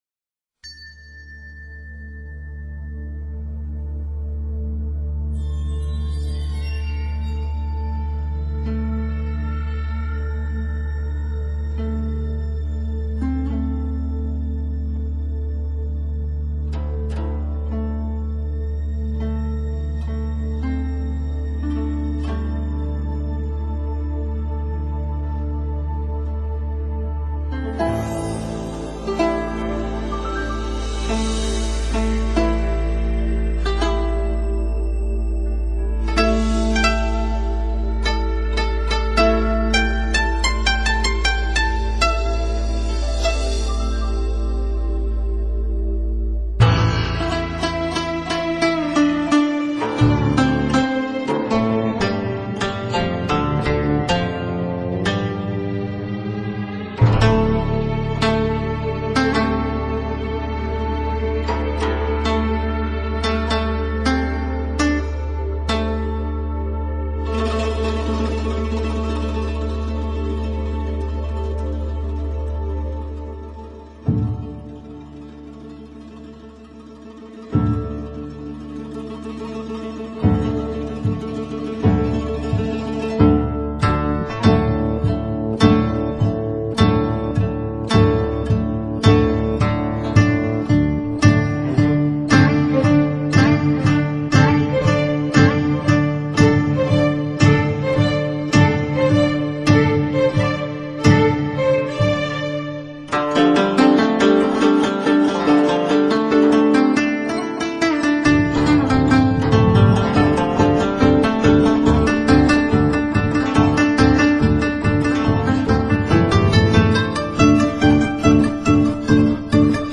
前半部的琶音气氛昂扬
中部大提琴舒缓悠扬，再次阐明“月”恒久祥和的襟怀。后半部器乐合奏，旋律热烈而跳跃，为“月”主诣作了最明亮的提升。